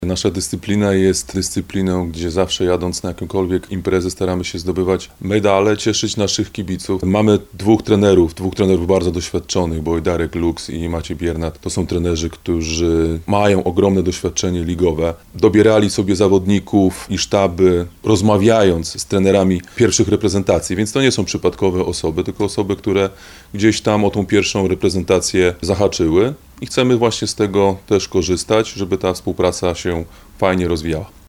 – mówi prezes Polskiego Związku Piłki Siatkowej Sebastian Świderski